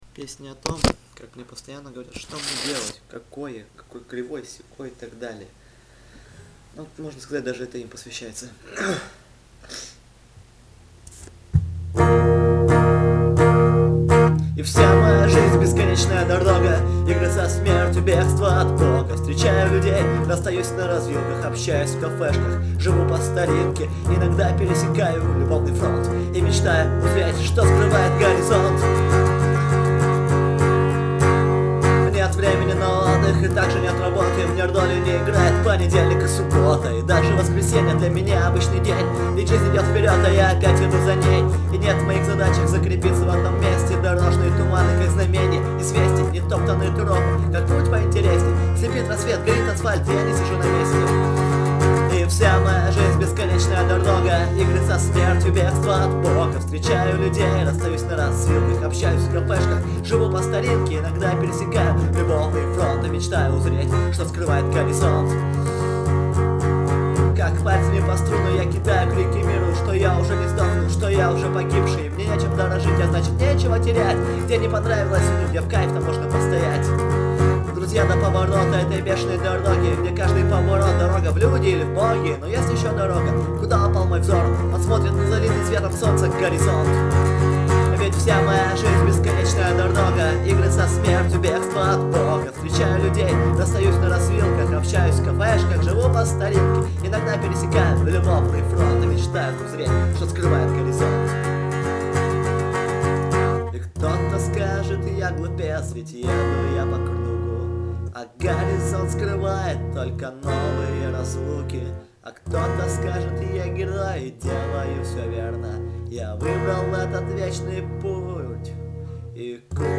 Категория: Акустика